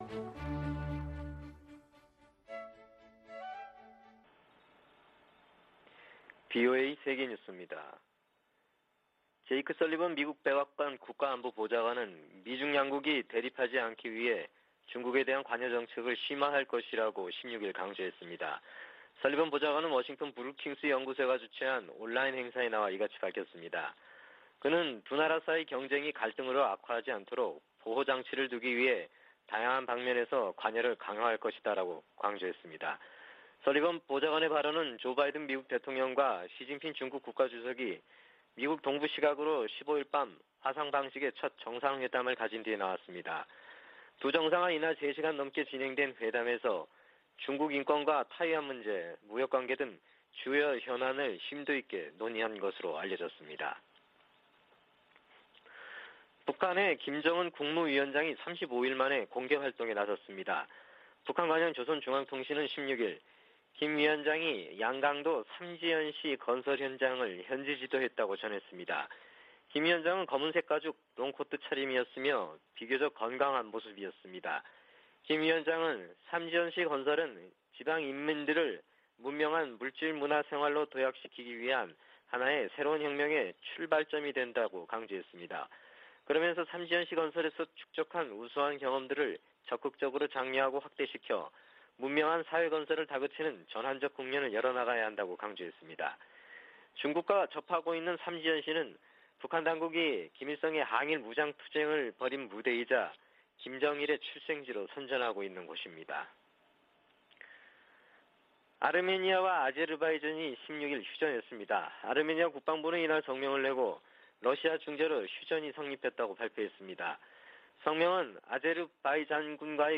VOA 한국어 아침 뉴스 프로그램 '워싱턴 뉴스 광장' 2021년 11월 17일 방송입니다. 미 국방부 부차관보는 실질적 ‘대북 조치’ 가 준비 돼 있다면서도, 위협 행위는 간과하지 않겠다고 강조했습니다. 디 셔먼 미 국무부 부장관이 이번 주 한국과 일본의 외교차관과 워싱턴에서 연쇄 회동을 가집니다.